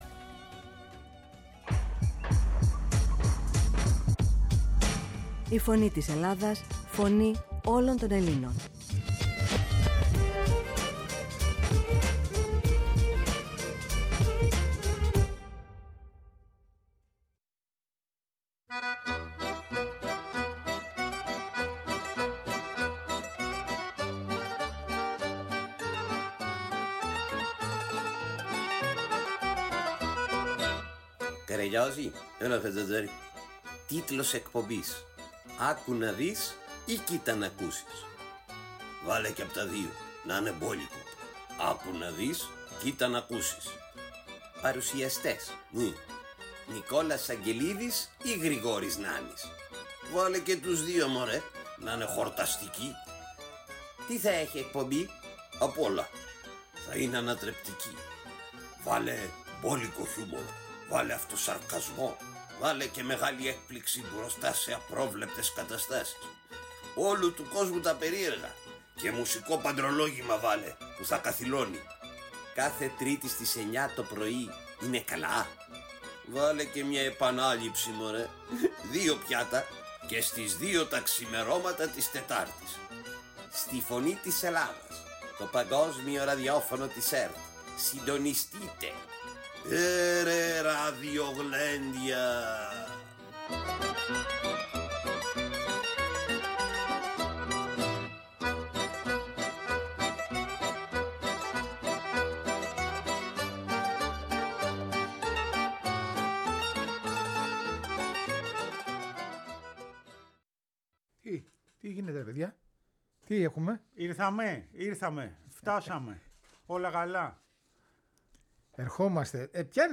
Τέλος ακούμε τον ύμνο του Οδυσσέα Κορδελιού και μαθαίνουμε σημαντικά στοιχεία για την ιστορική ομάδα της Βόρειας Ελλάδας